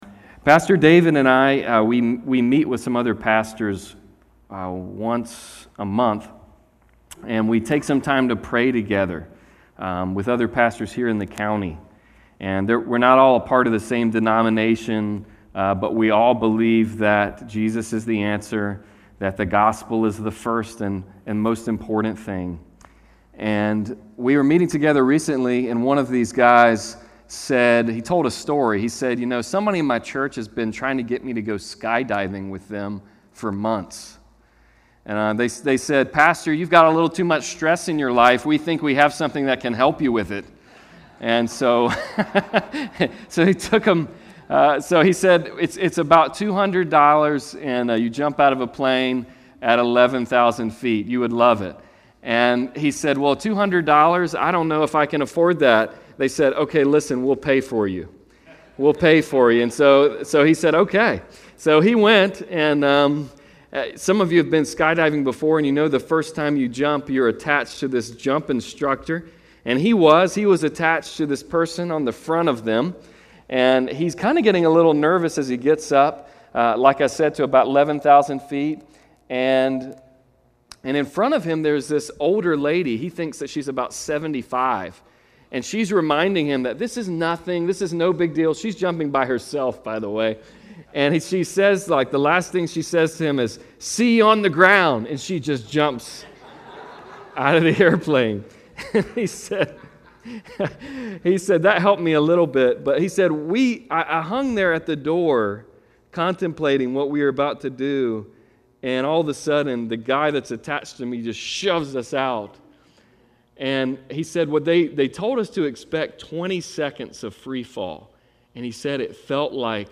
Passage: Mark 5:21-34 Service Type: Sunday Service